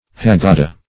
Haggada \Hag*ga"da\ (h[a^]g*g[aum]"d[.a]), n.; pl. Haggadoth